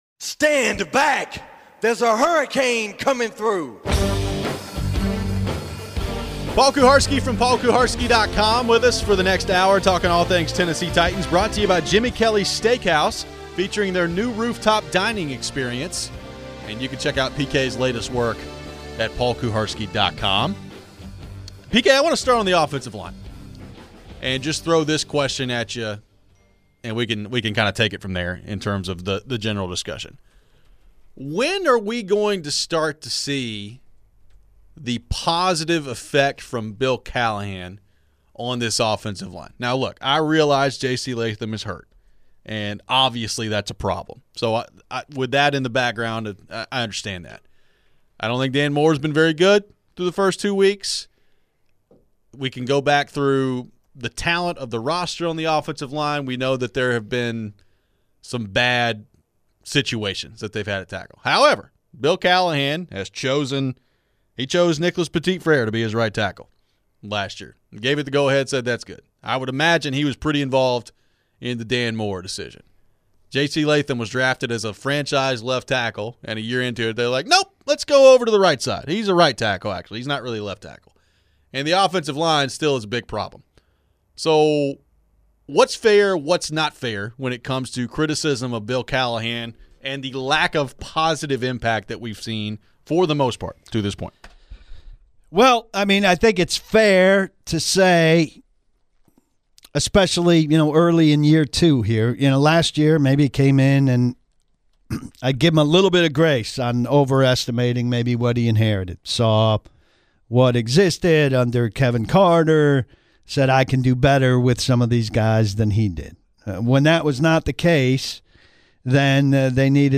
What do we think of the early performance from the OL? Who is to blame for all the penalties? We take your phones and questions.